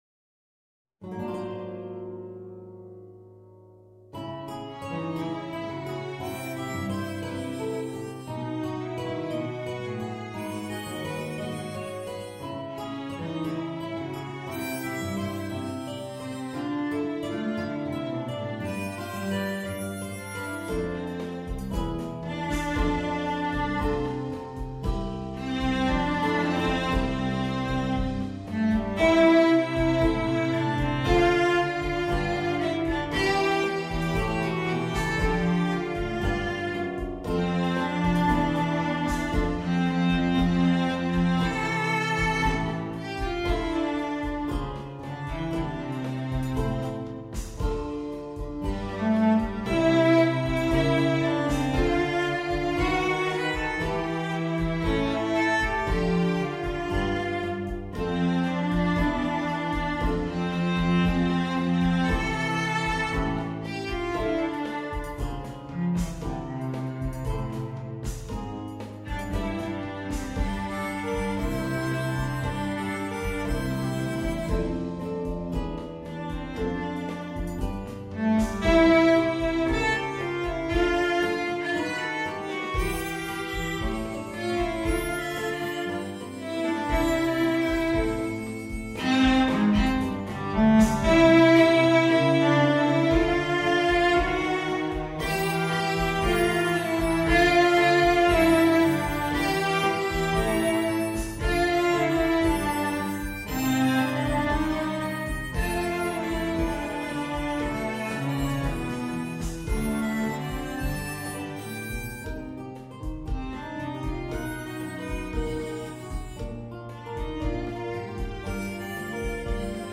Bankaránsrokkari fyrir The Icelandic Pop Orchestra